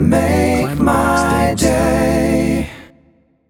“Make my day” Clamor Sound Effect
Can also be used as a car sound and works as a Tesla LockChime sound for the Boombox.